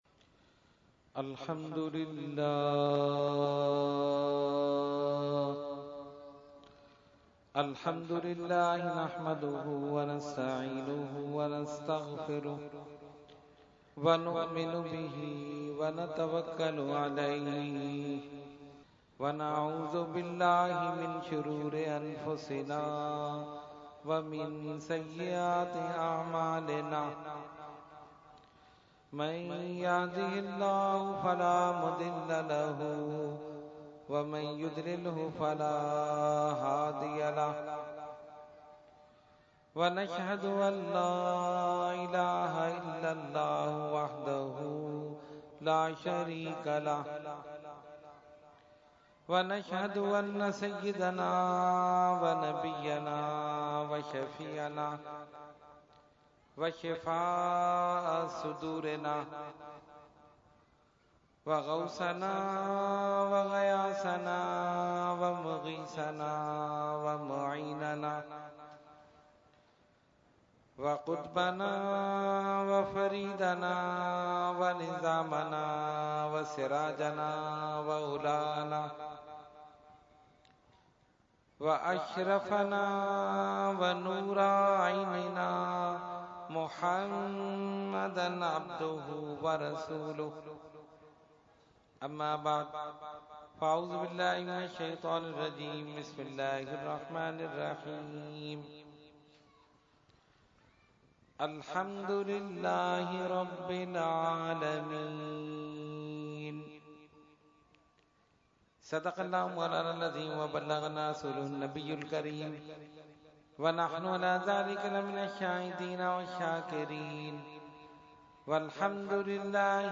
Category : Speech | Language : UrduEvent : Muharram 2016